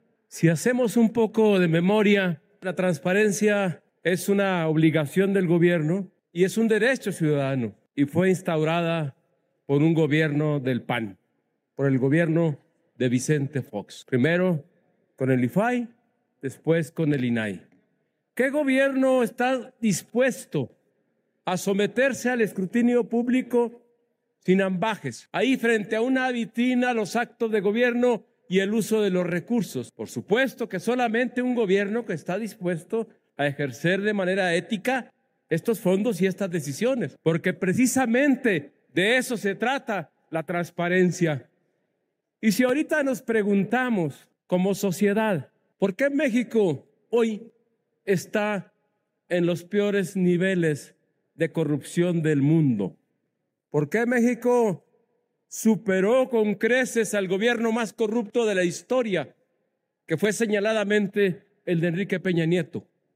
Durante su intervención en tribuna, Mario Vázquez, recordó que la transparencia es una obligación del gobierno hacia los ciudadanos y que fue un gobierno del PAN, encabezado por Vicente Fox, el que instauró el IFAI, hoy INAI.